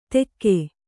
♪ tekke